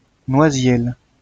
来自 Lingua Libre 项目的发音音频文件。 语言 InfoField 法语 拼写 InfoField Noisiel 日期 2019年2月6日 来源 自己的作品